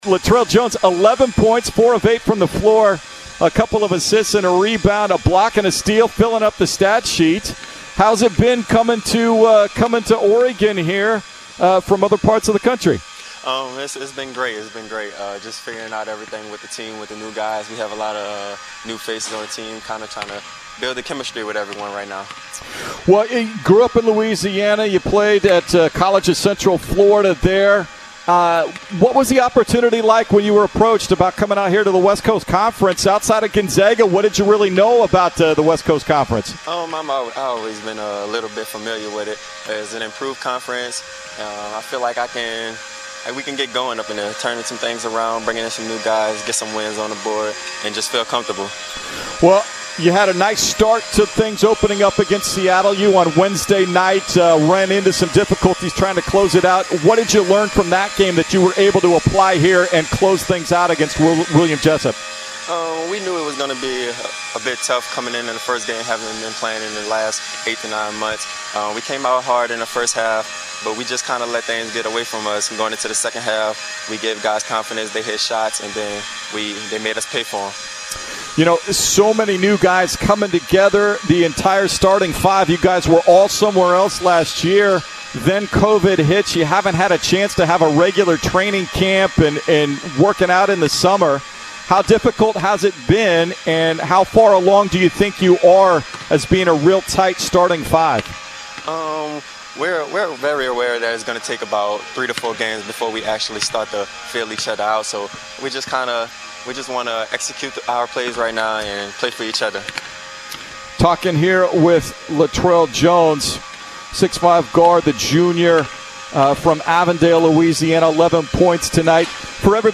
Men's Hoops Post-Game Interviews vs. William Jessup